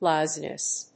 /ˈlaʊzinʌs(米国英語), ˈlaʊzi:nʌs(英国英語)/